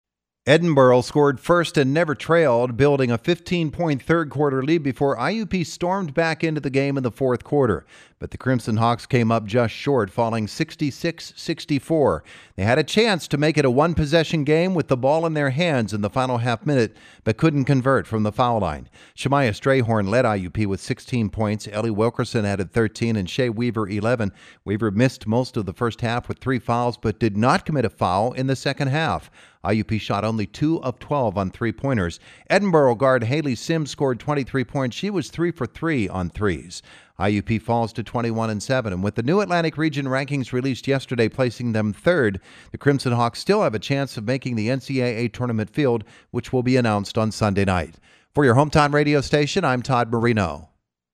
nws0580-tjm-iup-ladies-recap.mp3